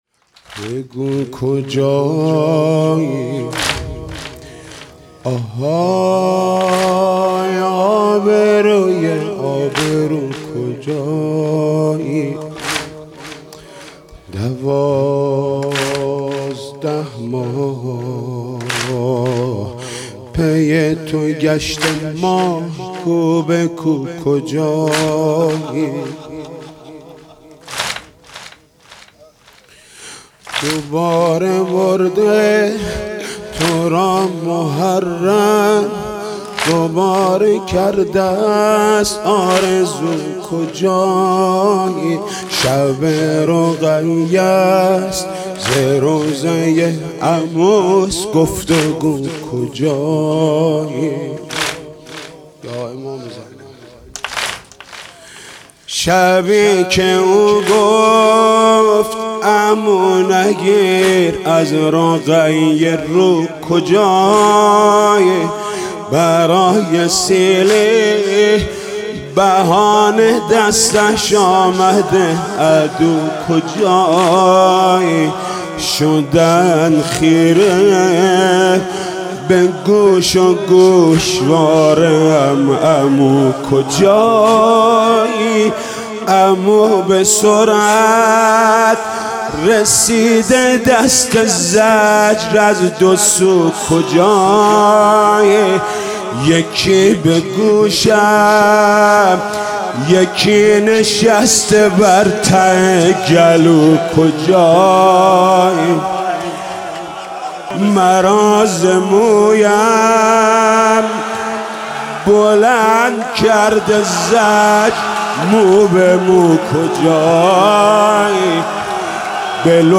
بگو کجایی آهای آبروی آبرو کجایی/ حاج محمود کریمی/ شب سوم محرم 96